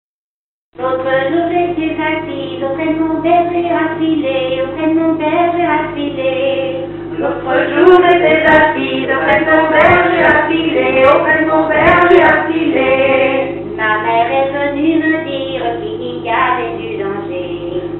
Usage d'après l'informateur gestuel : travail
Genre laisse
Pièce musicale inédite